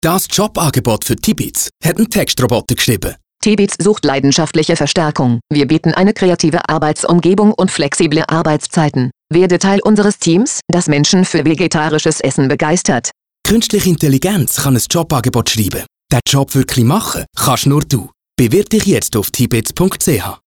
Die drei Radiospots und vier verschiedenen Online- und Plakatsujets zeigen auf humorvolle Art und Weise die Grenzen der künstlichen Intelligenz auf.
tibits_Radio_Jobangebot.mp3